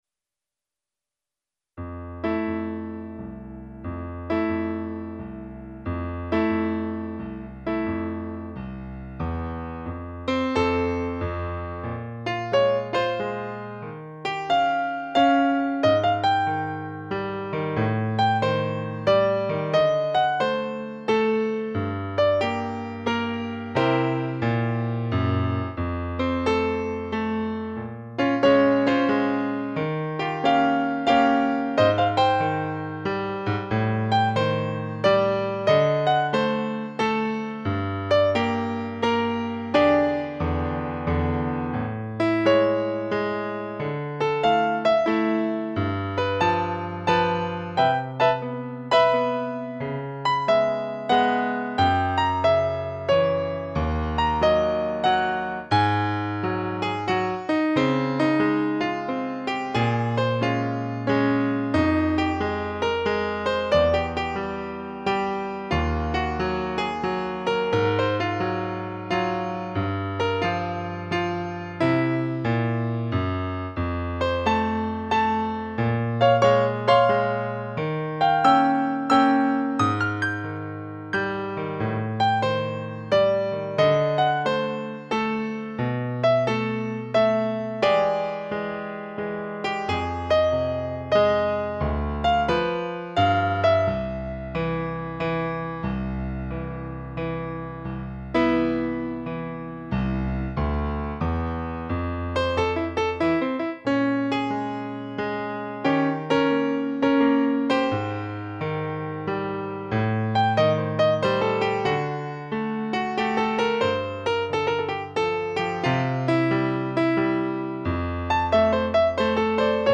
Jazz/Improvisierte Musik
Klavier (1)